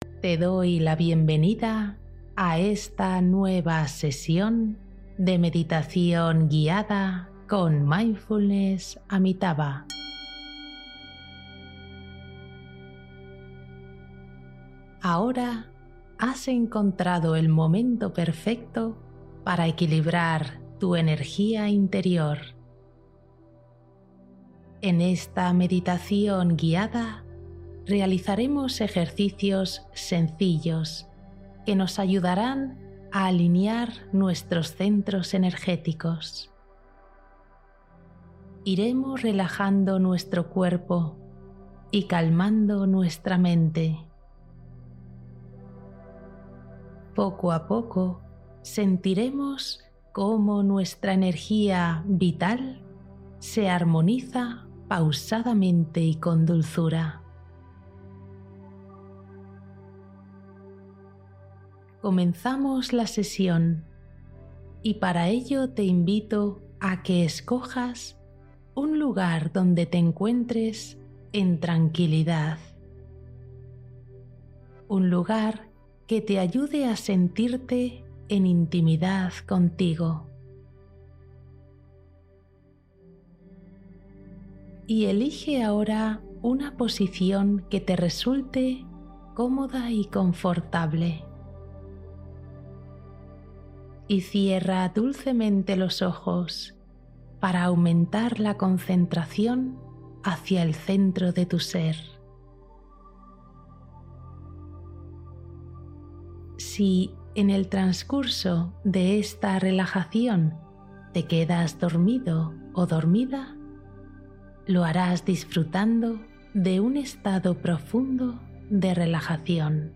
Alinea tus chakras y activa tu energía con esta meditación para dormir